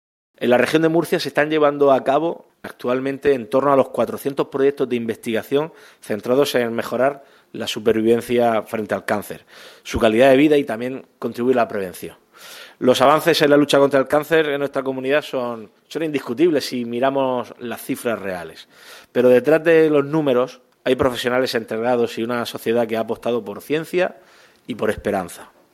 El presidente del Ejecutivo autonómico, Fernando López Miras, dio a conocer esta cifra durante la inauguración hoy en Murcia del IV Congreso de Supervivientes de Cáncer 'Llenos de Vida', de la Fundación Sandra Ibarra.
Sonido/ Declaraciones del presidente López Miras sobre los proyectos de investigación sobre el cáncer que se están desarrollando actualmente en la Región de Murcia.